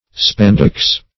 Spandex \Span"dex\